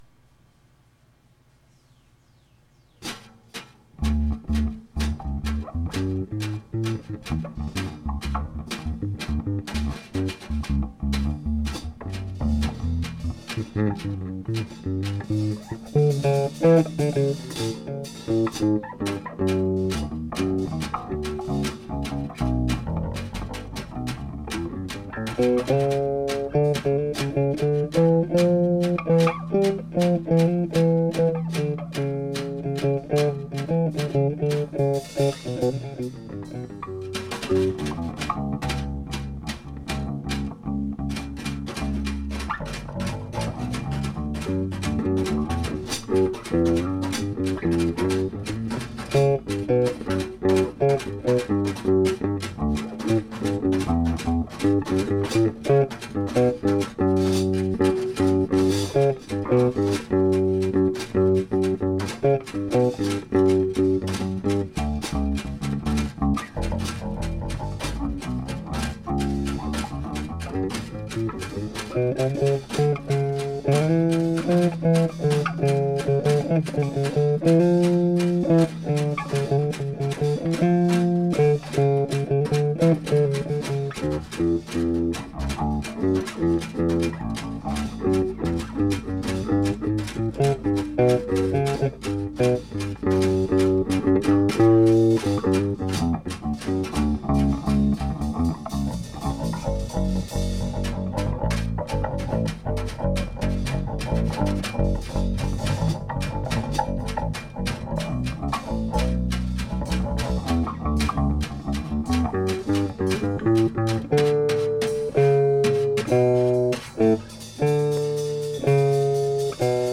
Jazz Improv...experimental.. etc....practice sessions...
bass, guitar..
percussion
gets going after a minute or two